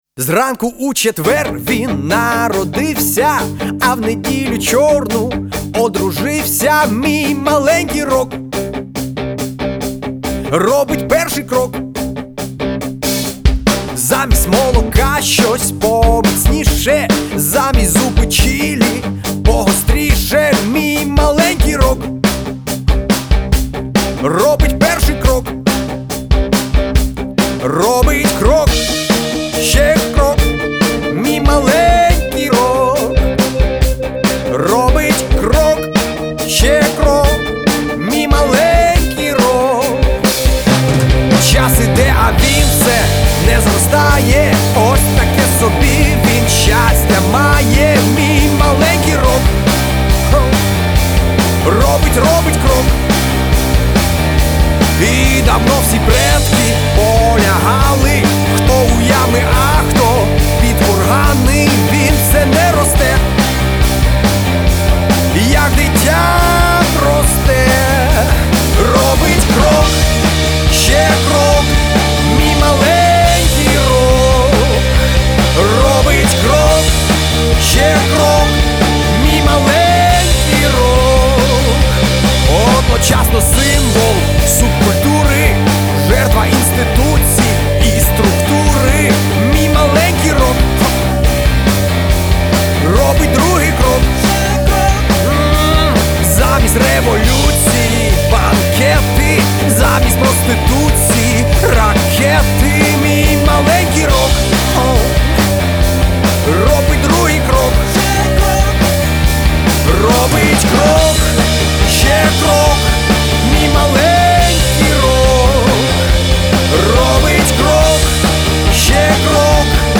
бас-гитарі
гітара, клавішні, бек-вокал.
ударні, программінг, бек-вокал та